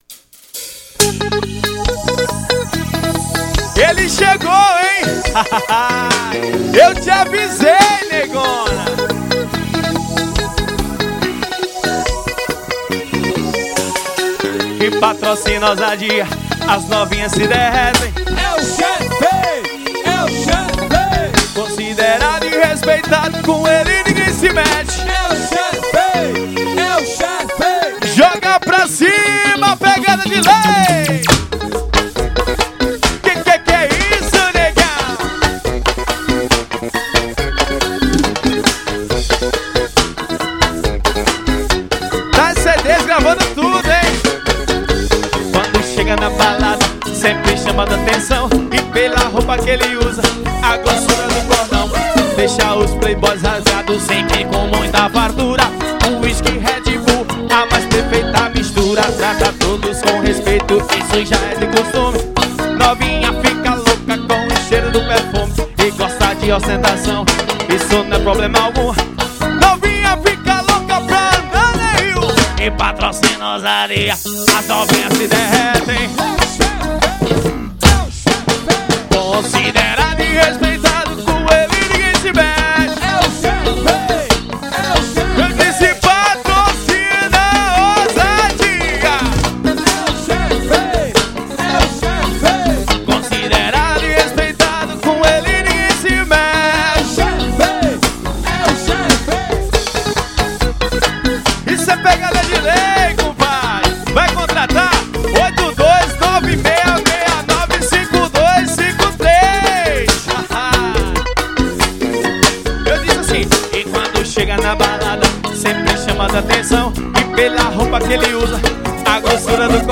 Forró Pegado.